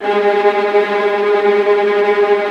VIOLINT A#-L.wav